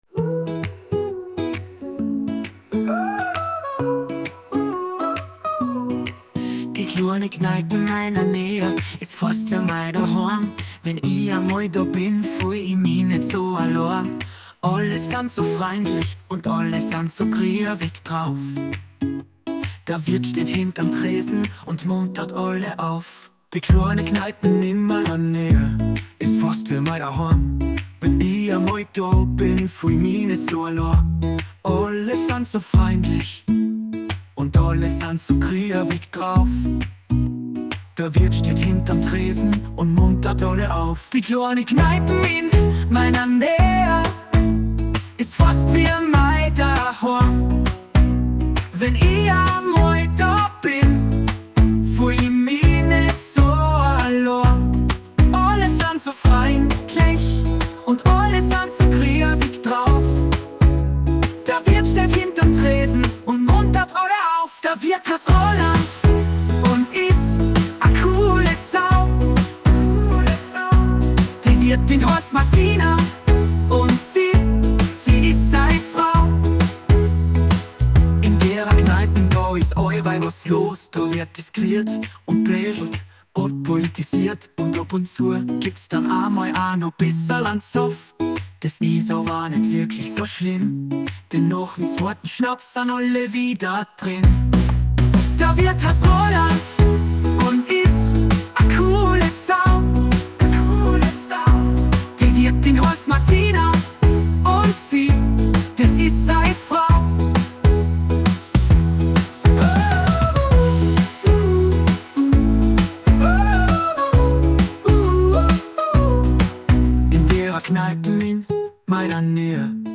Bayrische Musik, Liveband